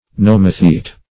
Search Result for " nomothete" : The Collaborative International Dictionary of English v.0.48: Nomothete \Nom"o*thete\, n. [Gr.